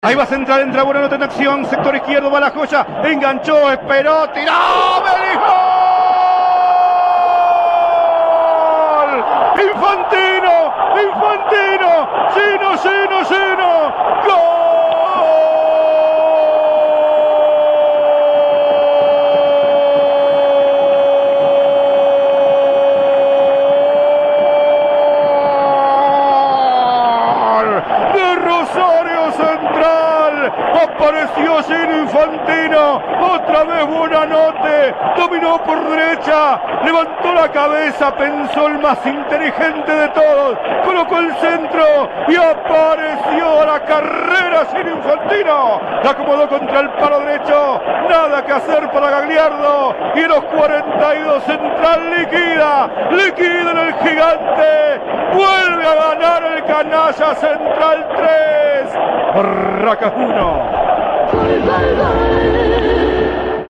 3º gol de Rosario Central (Infantino) - Relato